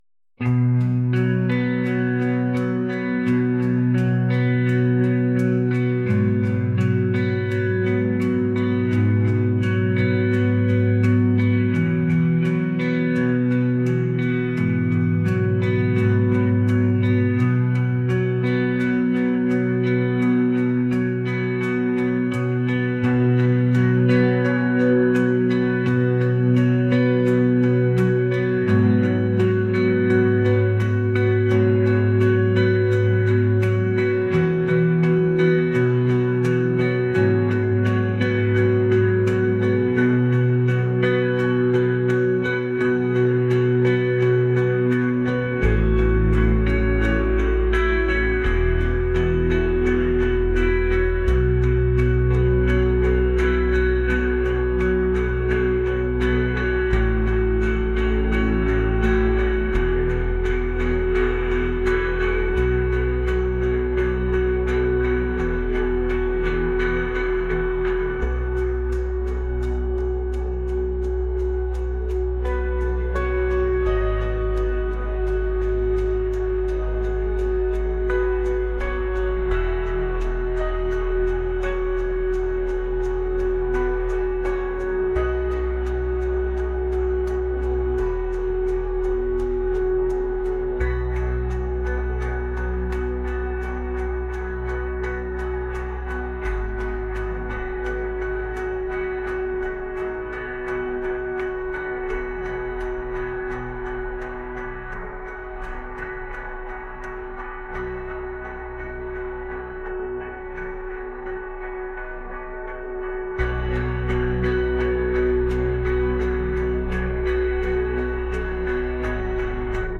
indie | ambient | cinematic